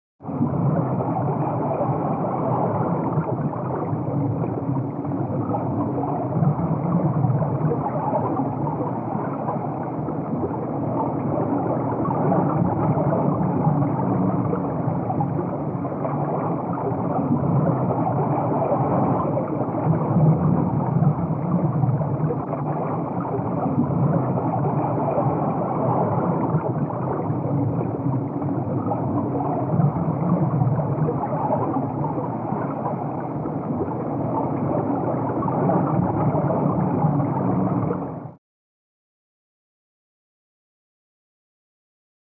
Heavy Rumble Underwater 1; With Slosh And Bubbles From The Deep Sea.